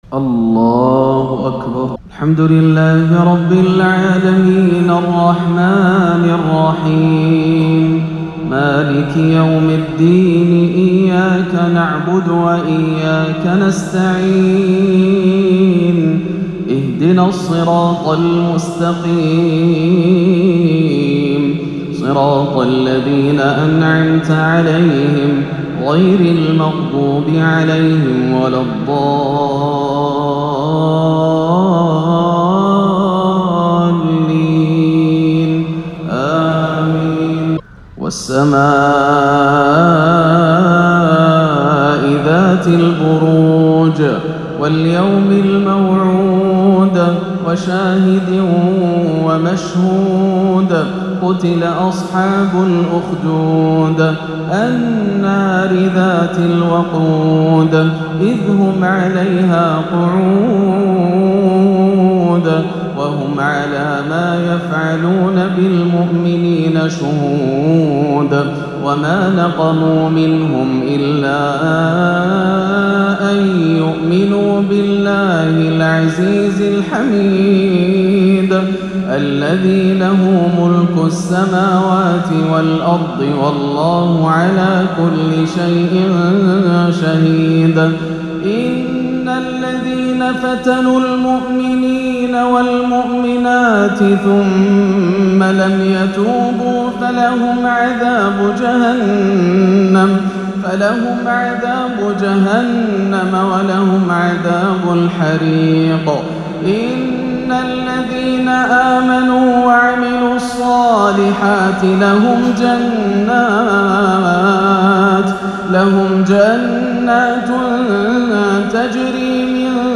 (يوم تبلى السرائر) ترتيل مؤثر لسورتي البروج والطارق - عشاء الثلاثاء 1-8 > عام 1439 > الفروض - تلاوات ياسر الدوسري